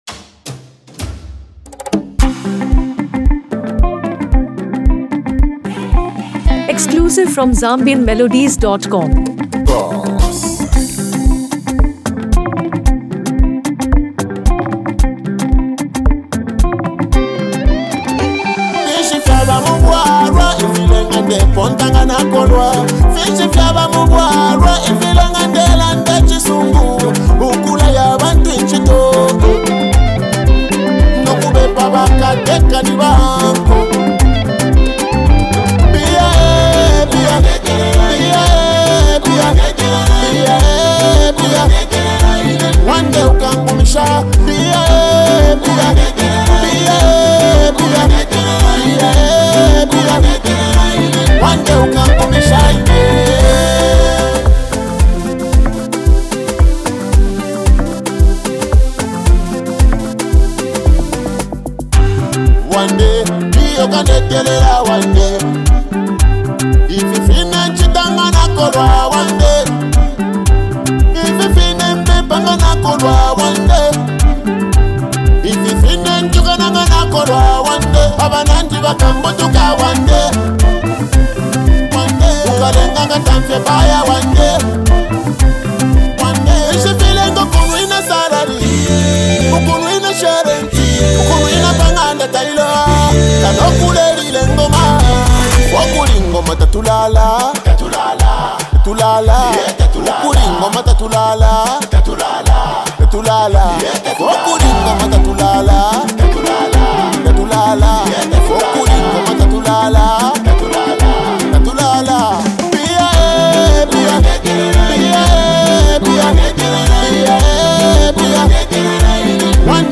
party anthems
dancehall-inspired vocal style
The song offers nonstop groove, powerful dance energy